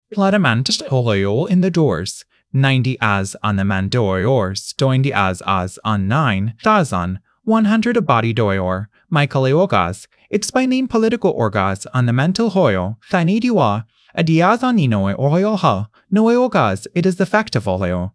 Chatterbox TTS — модель Text-to-Speech с открытым исходным кодом для генерации естественного голосового аудио из текста.
Когда я попытался создать запись с прочтением русского стиха и включил проигрывание аудио, задумался, не ошибся ли я и не включил ли
запись с WAIC на китайском.
Да, это такая особенность некоторых моделей, не обученных на русском языке — пытаться бегло прочитать текст, не понимая его смысла и не зная букв.
chatterbox_tts_ru.wav